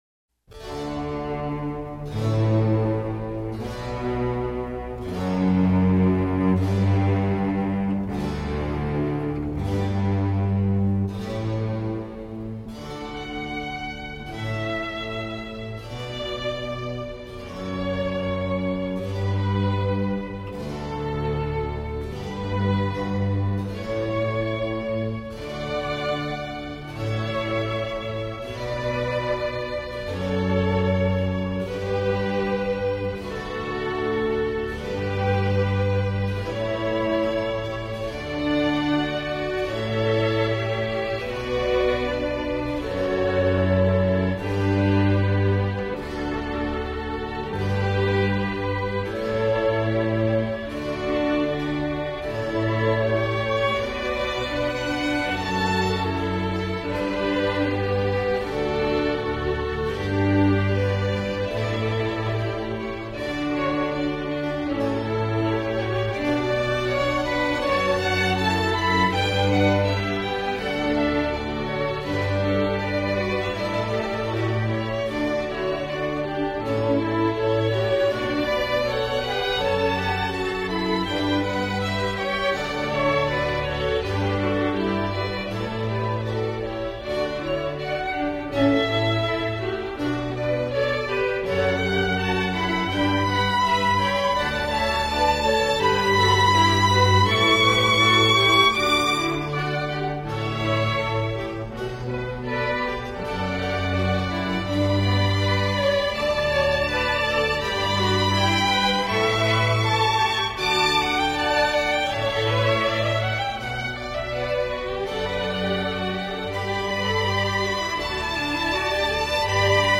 파헬벨_캐논과 지그 D장조.mp3